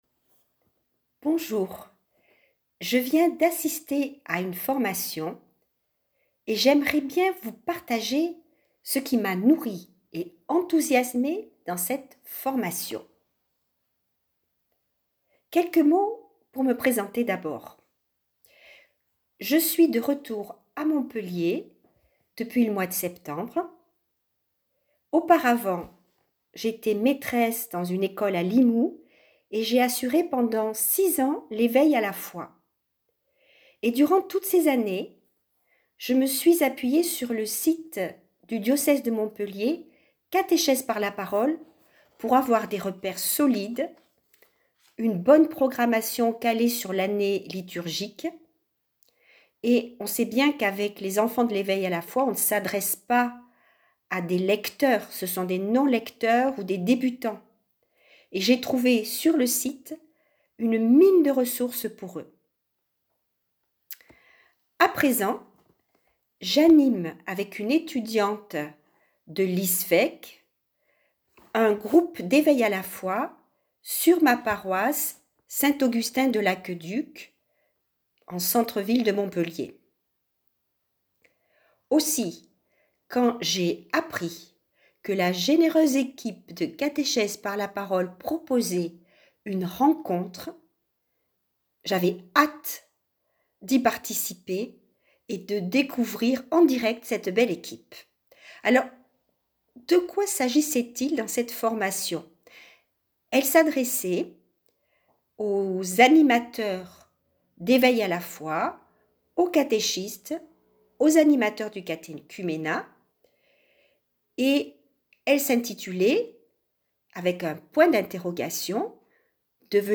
Témoignage audio d'une participante